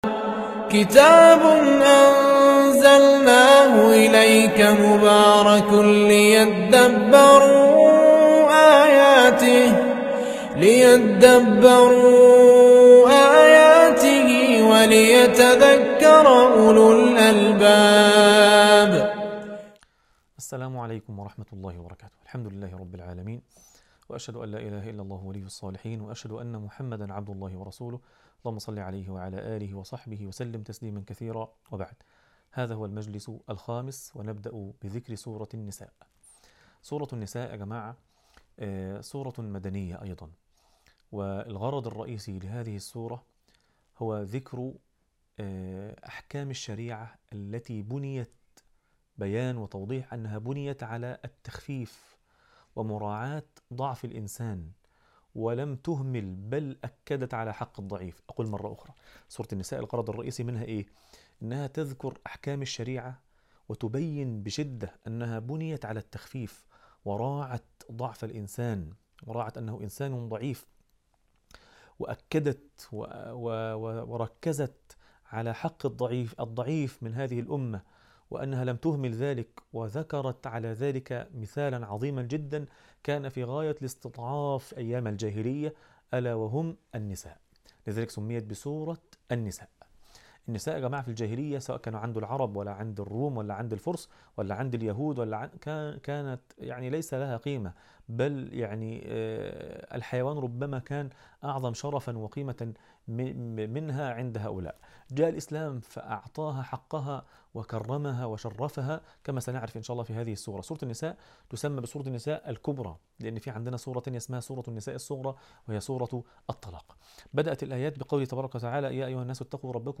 عنوان المادة الدرس الخامس - مقاصد السور و هدايات الآيات تاريخ التحميل السبت 20 سبتمبر 2025 مـ حجم المادة 12.08 ميجا بايت عدد الزيارات 42 زيارة عدد مرات الحفظ 26 مرة إستماع المادة حفظ المادة اضف تعليقك أرسل لصديق